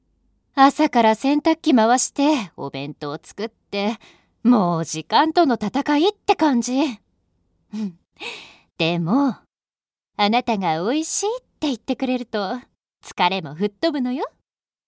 【声優ボイスサンプル】
ボイスサンプル6（母親）[↓DOWNLOAD]